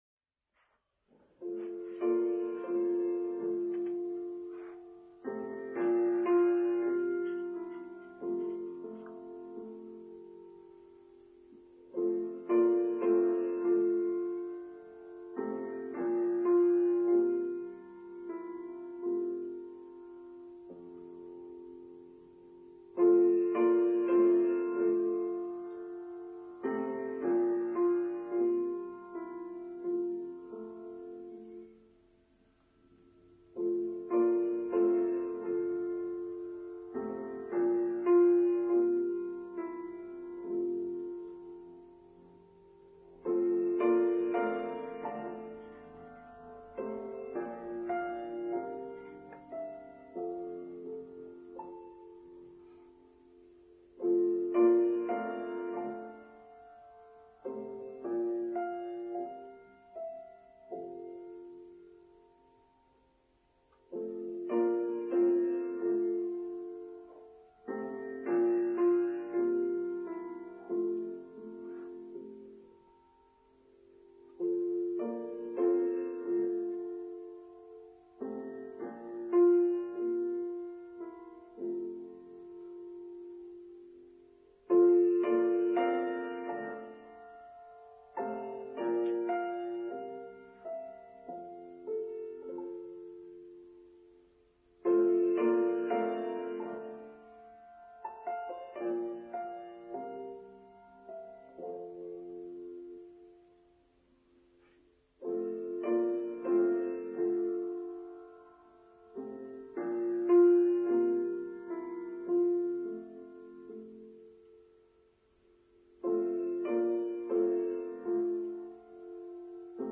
Being Who We Really Are: Online service for Sunday 15th August 2021